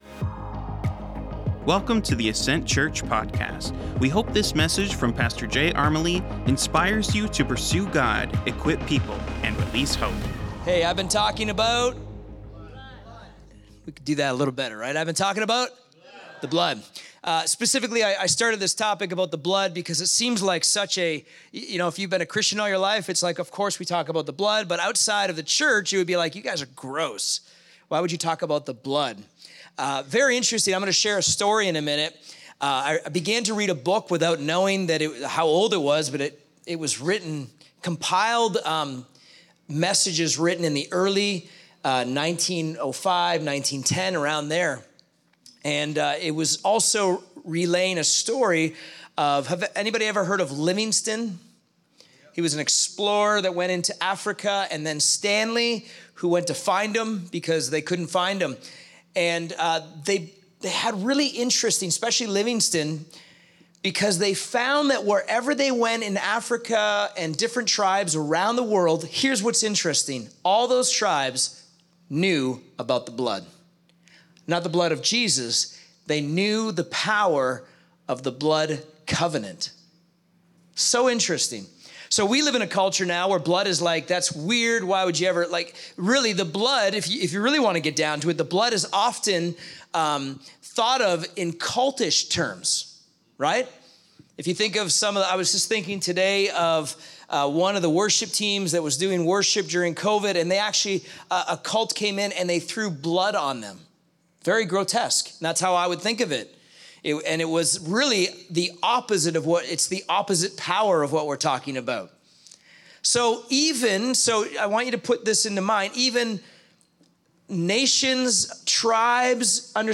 Current Weekly Sermon